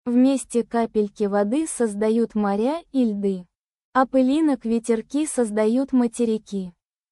Голос «Маргарита», синтез речи нейросетью